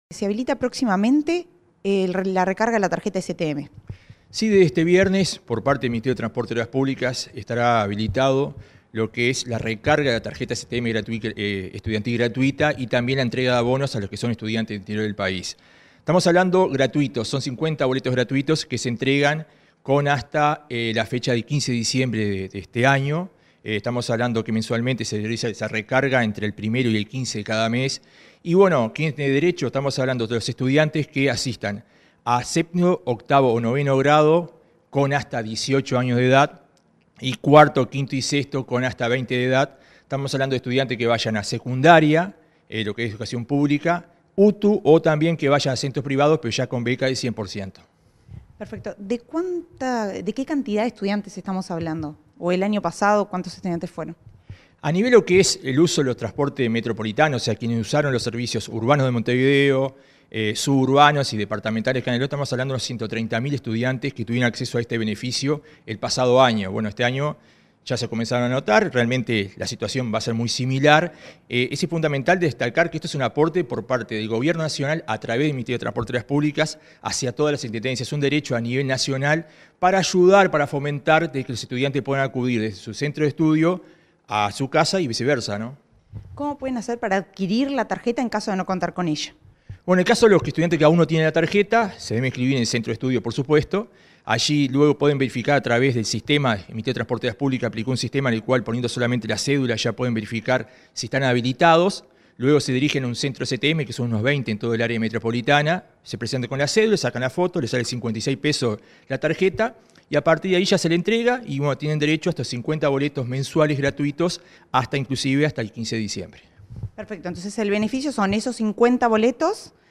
Entrevista al coordinador del área metropolitana del MTOP, Joselo Hernández
Comunicación Presidencial dialogó con el coordinador del Área Metropolitana del Ministerio de Transporte y Obras Públicas (MTOP), Joselo Hernández,